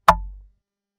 Button Click.mp3